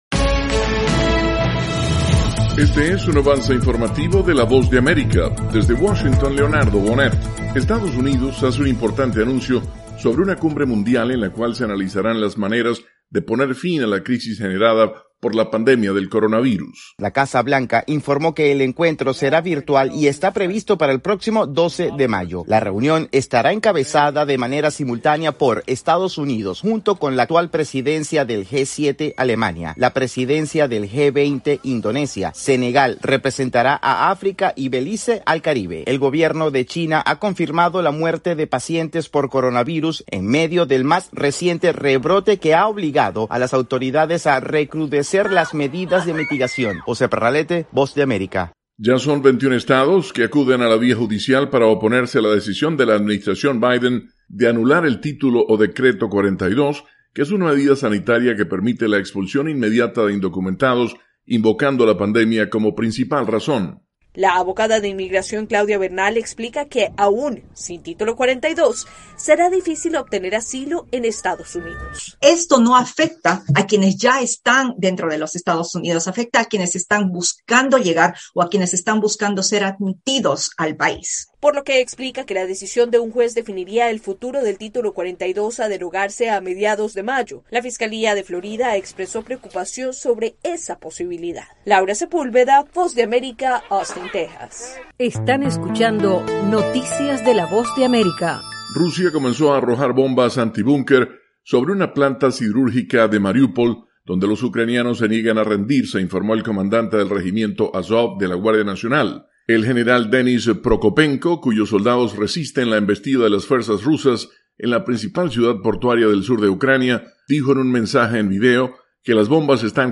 Avance Informativo - 7:00 PM